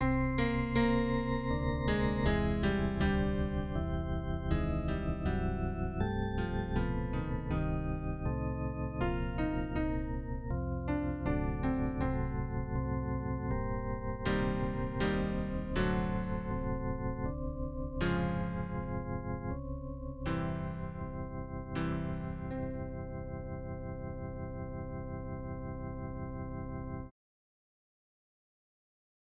Tempo MP3 Standard Voix au piano Date